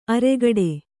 ♪ aregaḍe